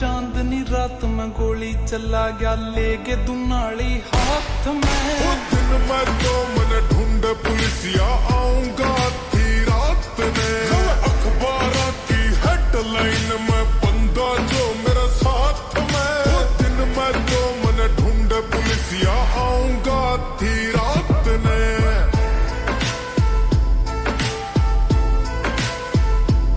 Rajasthani songs
• Simple and Lofi sound
• Crisp and clear sound